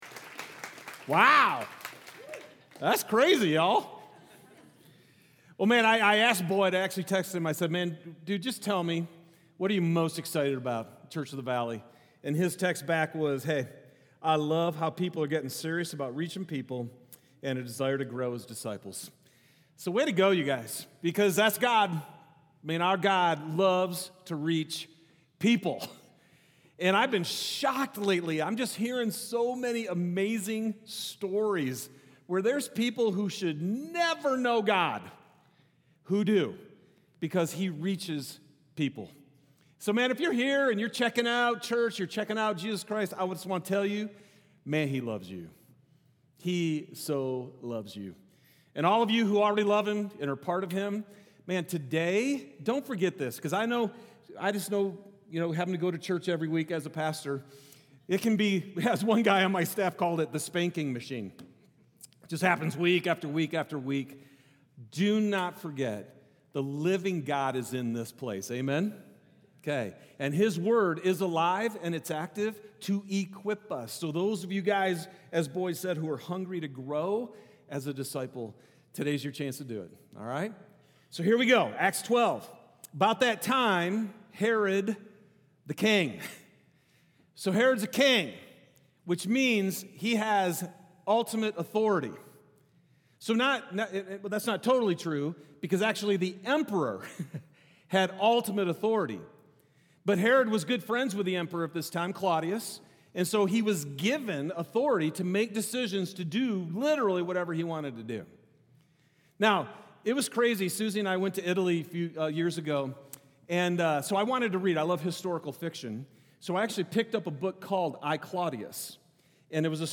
Vision & Values Meet Our Team Statement of Faith Sermons Contact Us Give Those People | Acts 12 November 23, 2025